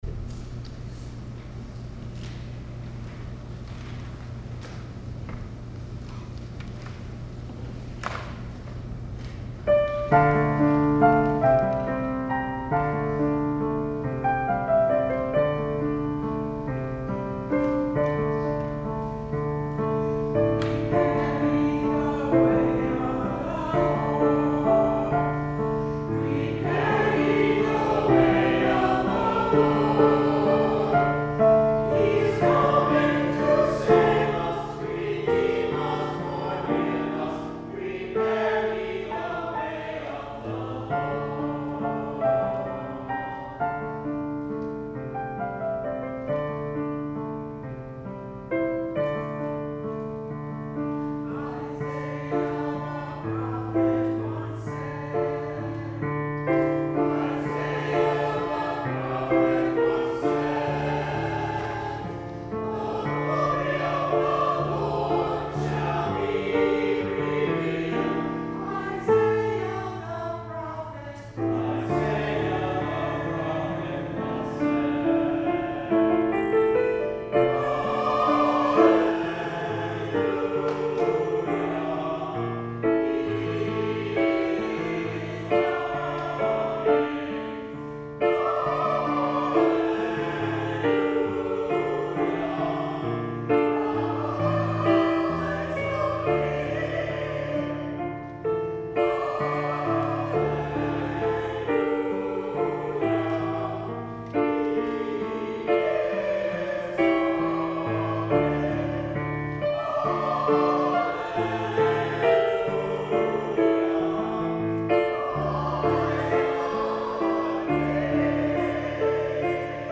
Music from December 9, 2018 Sunday Service